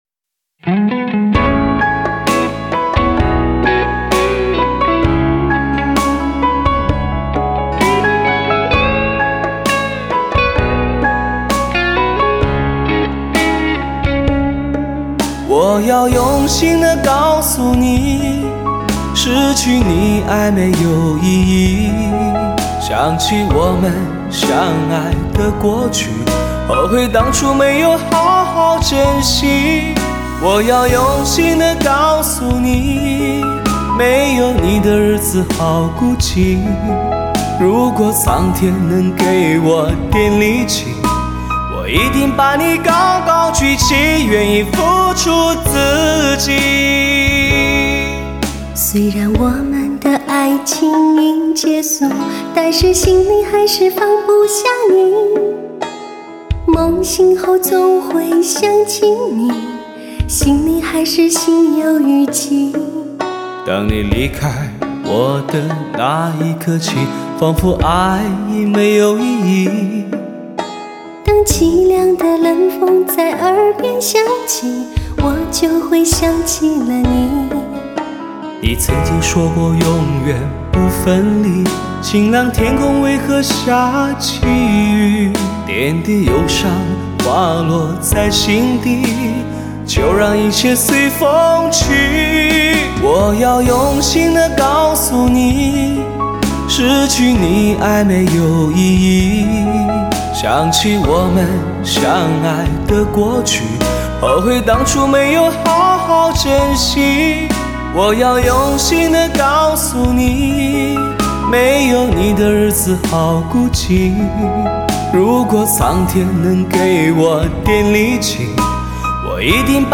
最让人感动的对唱情歌，感情是一种不可抗拒的毒，